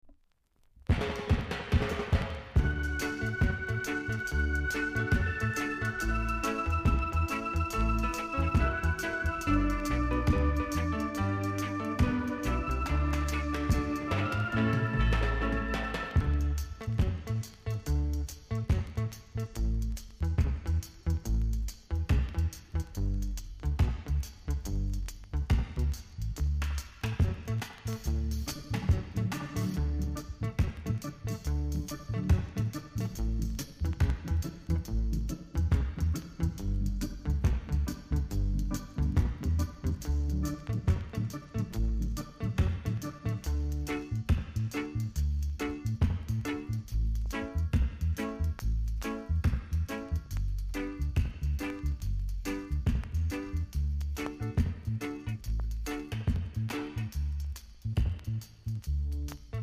コメント RARE REGGAE VOCAL!!※VERSIONのフェードアウト前で少しプチパチします。